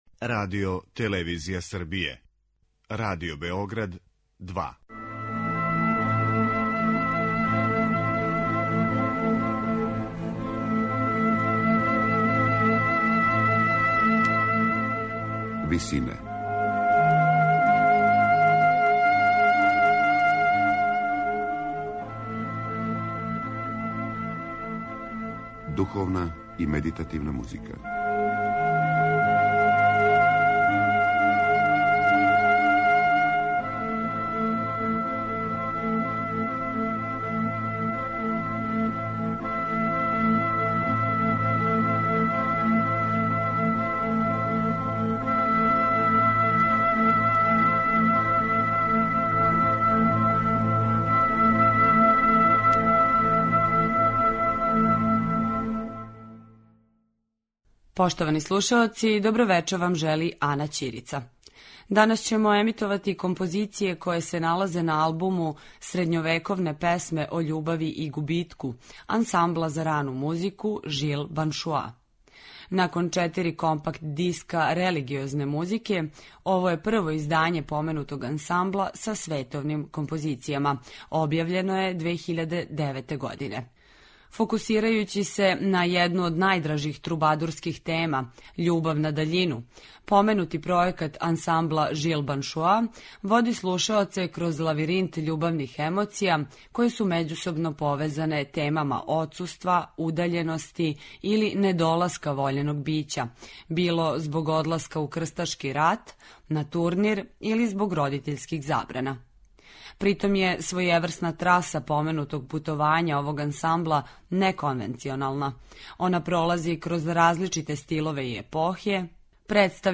Орландо ди Ласо – Мадригали